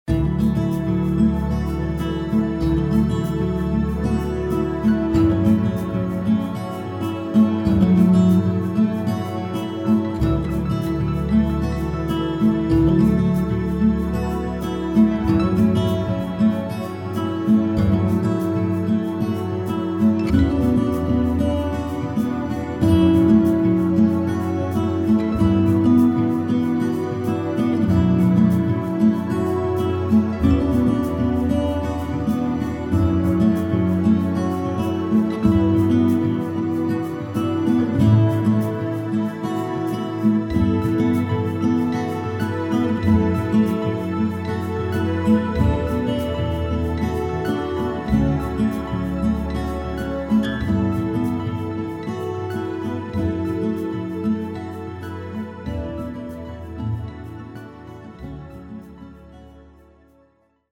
BGM试听: